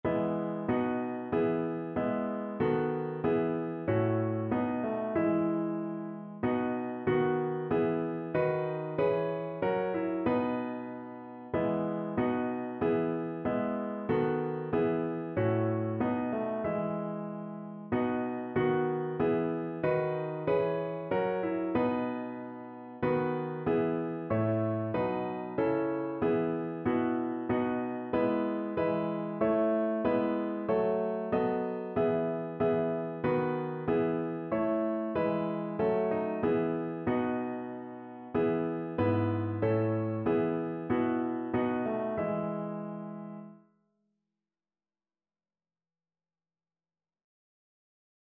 Notensatz 1 (4 Stimmen gemischt)
• gemischter Chor [MP3] 755 KB Download